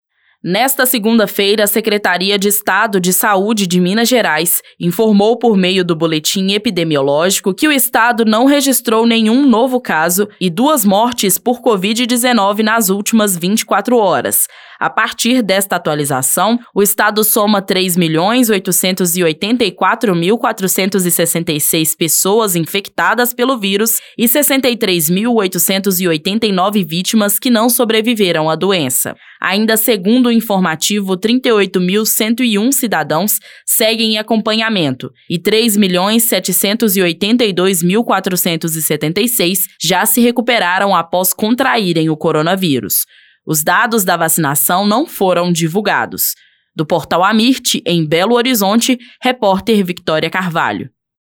AMIRT Boletim Epidemiológico Boletins Diários Coronavírus Destaque Notícias em áudio SaúdeThe estimated reading time is less than a minute